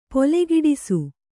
♪ polegiḍisu